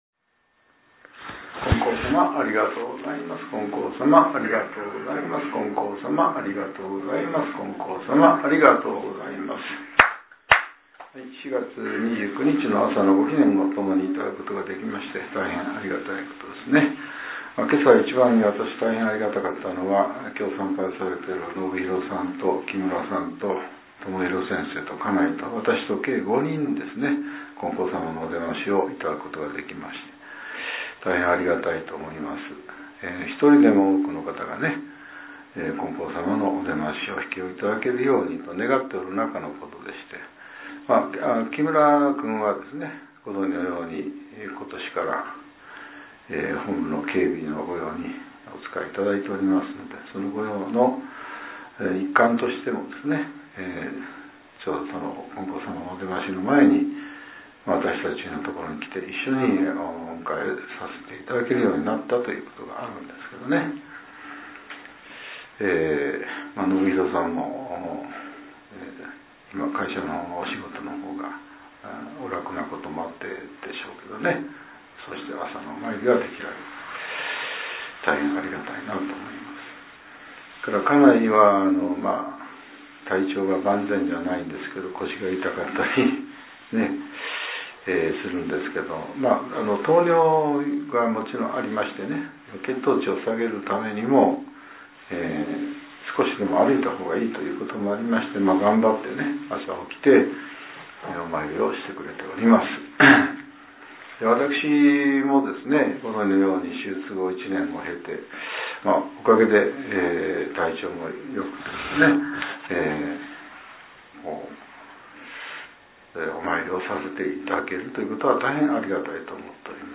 令和７年４月２９日（朝）のお話が、音声ブログとして更新されています。